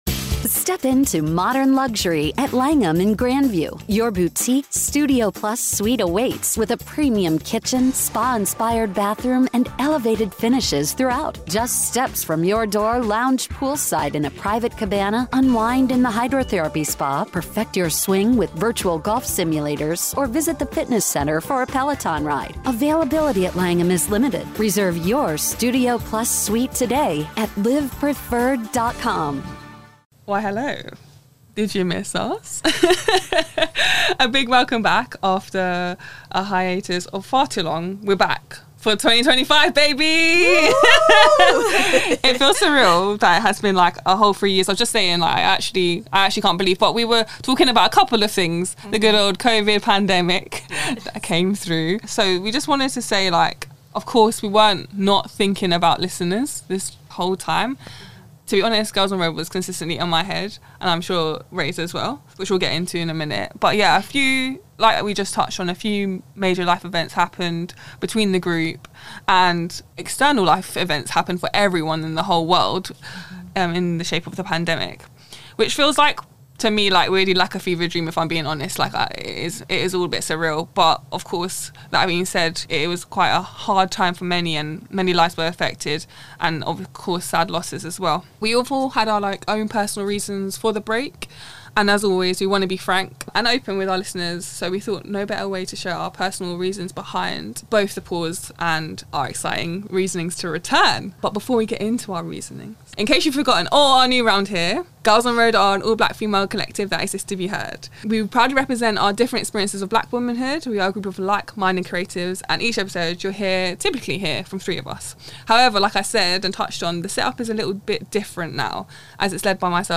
hosted by two OGs. We'll be exploring the topic of goals: the actions needed to work towards them and how we manage to achieve them. Gyals on Road is an audio collective of black femme creatives.